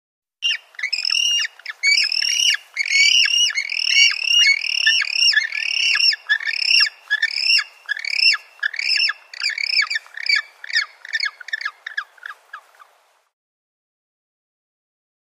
Penguin Chirps. Several Penguins Chirp With Ocean Ambience In The Background. Medium Perspective.